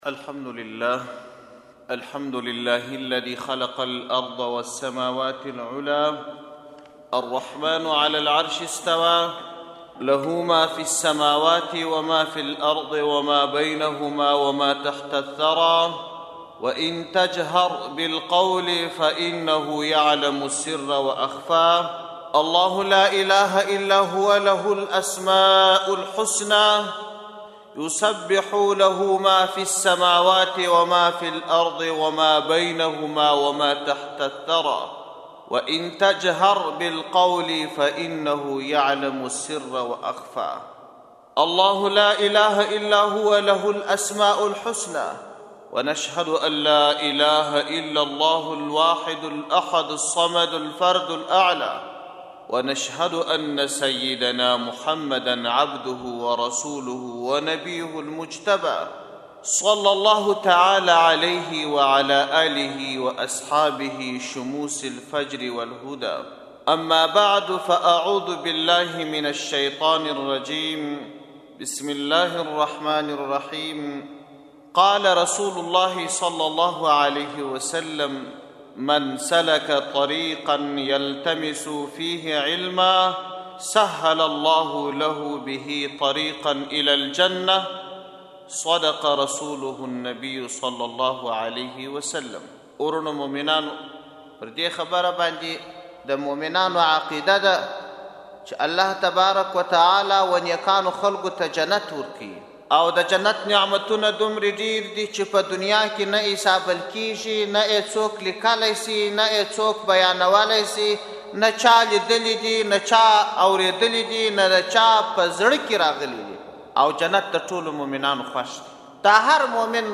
اصلاحي بیان